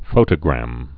(fōtə-grăm)